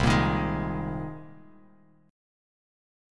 Cm7#5 chord